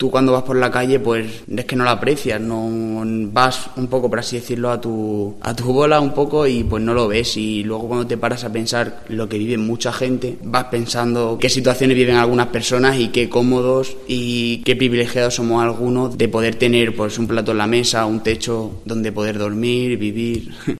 voluntario de Cáritas explica su experiencia